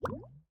drip_lava2.ogg